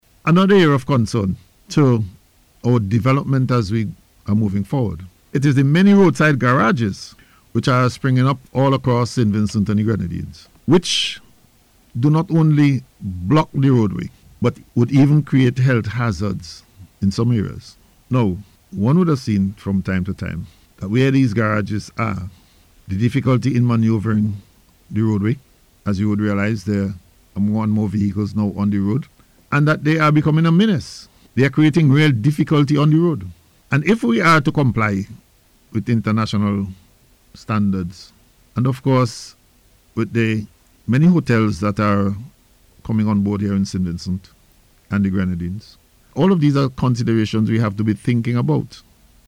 The Minister raised the issue on NBC’s Face to Face Program this morning, noting that the situation has worsened.